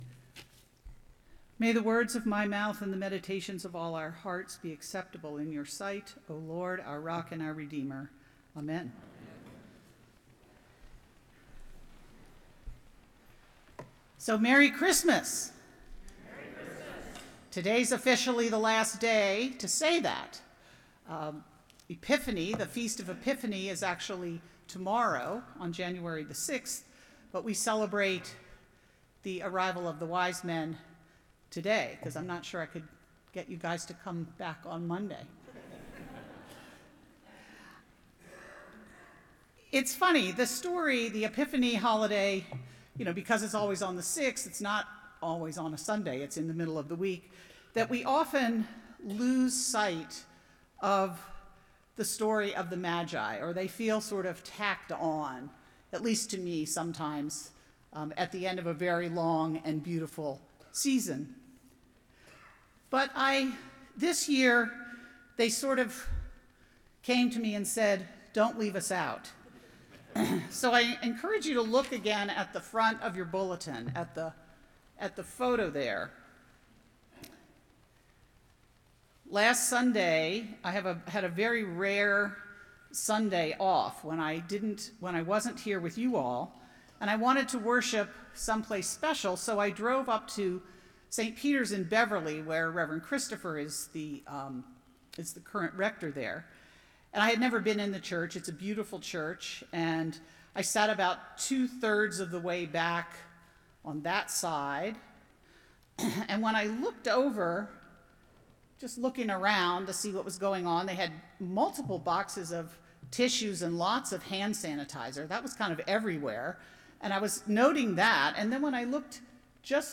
Épisode de l’émission · Trinity Episcopal Sermons, Concord, MA · 05/01/2025 · 12 min